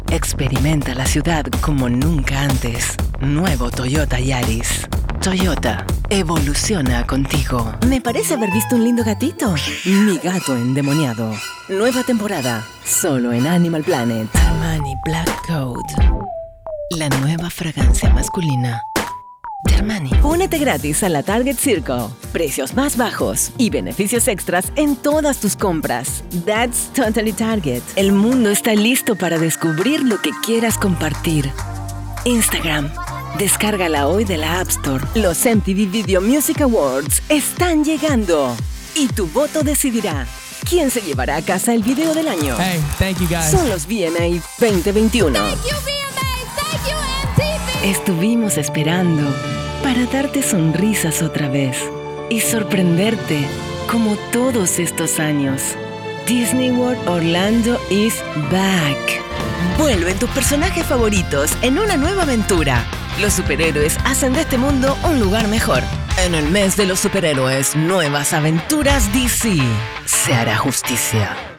DEMO COMERCIAL LATAM 2023
A medida que transcurre el año voy eligiendo algunos scripts con los que me sentí muy cómoda a la hora de interpretar y grabar.
Como artista de la voz es importante manejar el llamado español neutro o global, con el que las marcas internacionales pueden alcanzar simultáneamente a todos los países de habla hispana mas la población latina residente en otras latitudes.